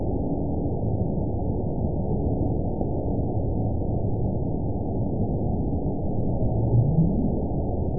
event 922803 date 04/07/25 time 06:01:16 GMT (2 months, 1 week ago) score 9.14 location TSS-AB02 detected by nrw target species NRW annotations +NRW Spectrogram: Frequency (kHz) vs. Time (s) audio not available .wav